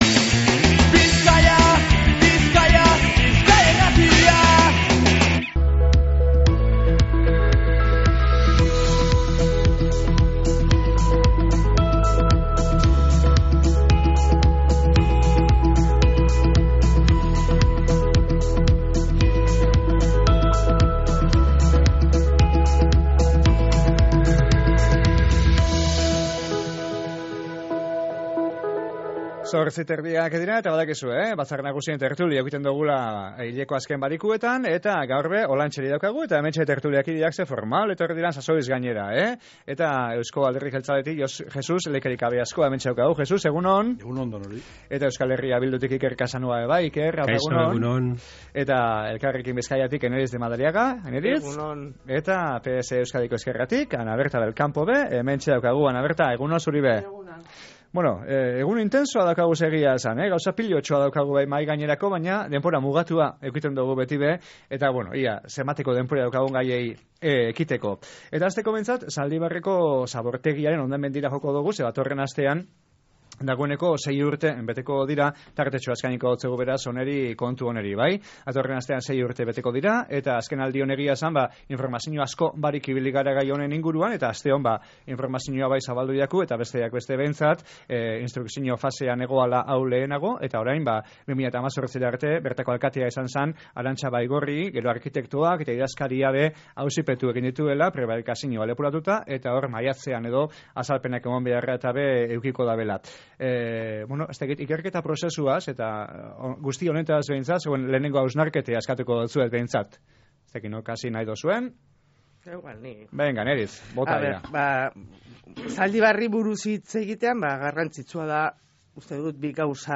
EAJ, EH Bildu, Elkarrekin Bizkaia eta PSE-EEko batzarkideek emon deuskue euren eritxia
Bizkaitarrok geure bizimoduaz eta nabarmentzen ditugun arazoez be egin dogu berba tertulian